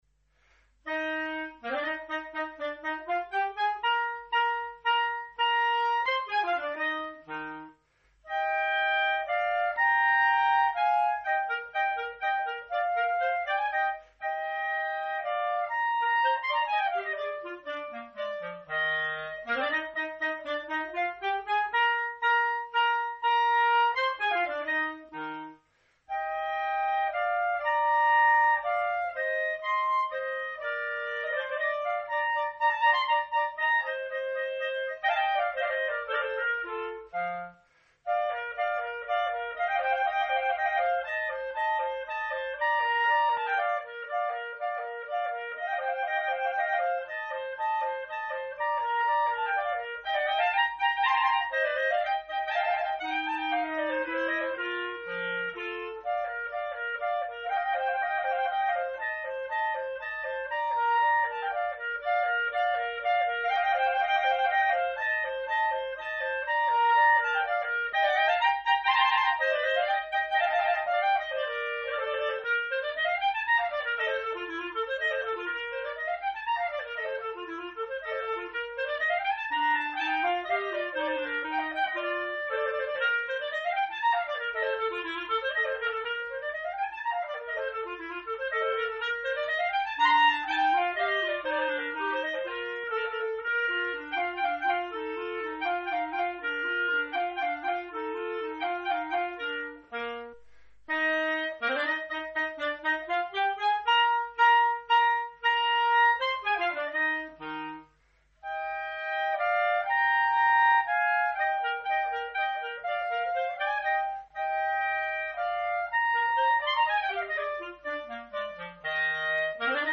Duo N° 2 opus 5 en fa M.
1e mouvement, Allegro assai (5'43)